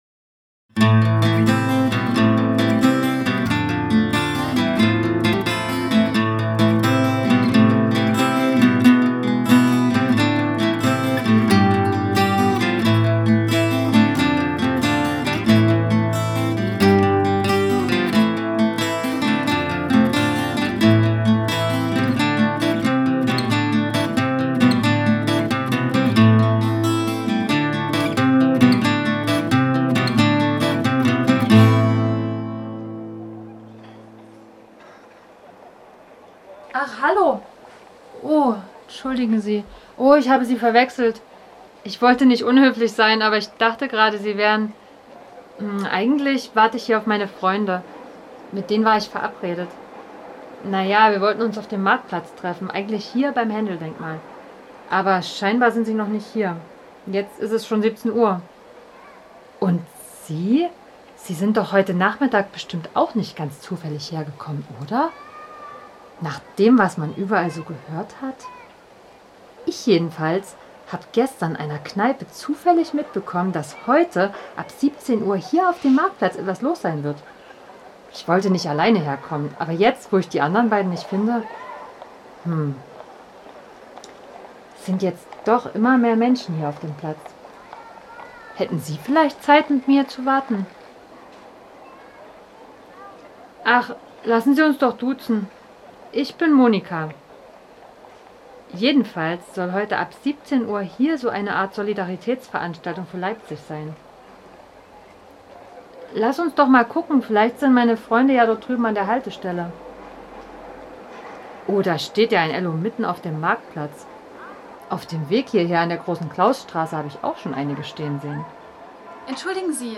Der Audiowalk vom Marktplatz bis zur Georgenkirche lädt dazu ein, als Mitglied einer Demonstrant:innengruppe die Atmosphäre dieses Abends greifbar nah zu erleben. Die Gespräche der anderen Gruppenmitglieder, Polizisten und anderen Personen sowie passende Soundscapes ermöglichen auf dem Weg ein immersives Erlebnis.